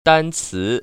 [dāncí] 딴츠